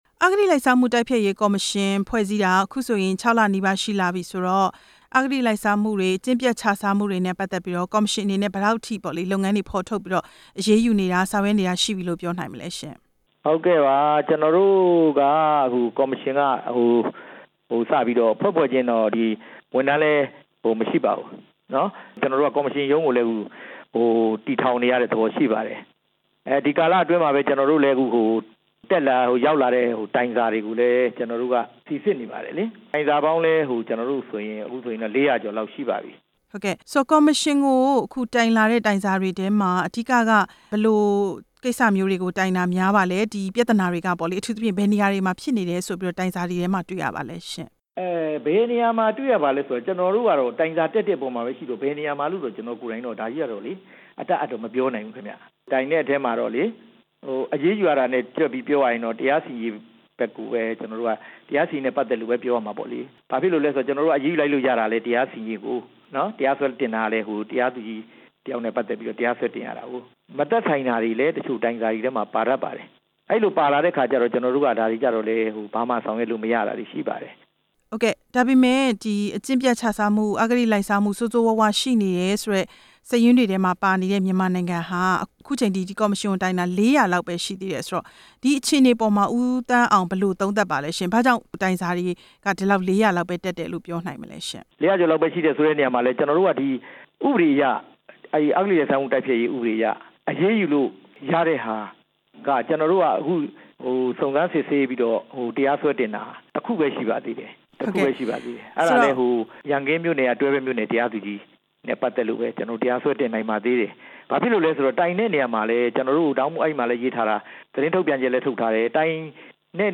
အဂတိလိုက်စားမှု တိုက်ဖျက်ရေးကော်မရှင်နဲ့ ဆက်သွယ်မေးမြန်းချက်